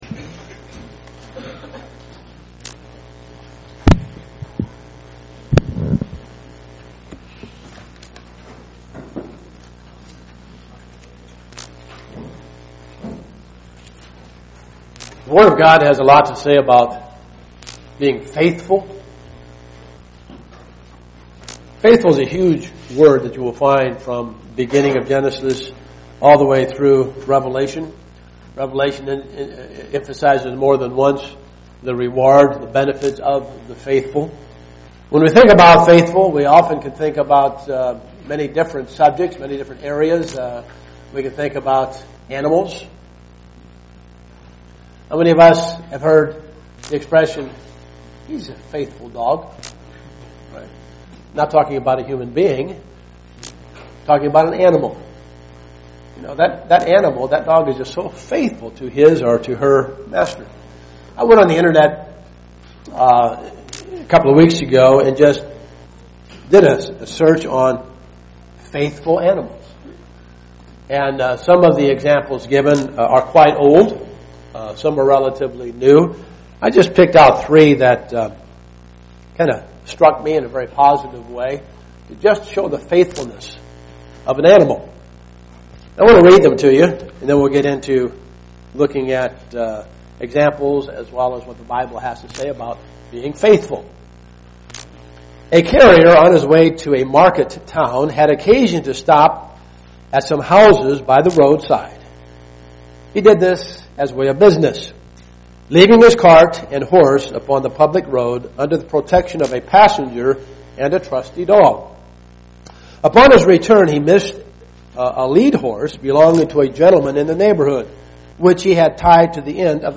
We are to be watchful of the three traits the world has to remove our faith as found in 1 John 2:15 UCG Sermon Studying the bible?